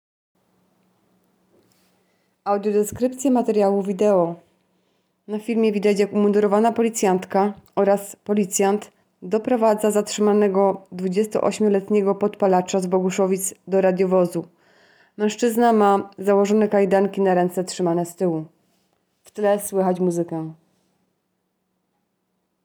Opis nagrania: Audiodeskrypcja.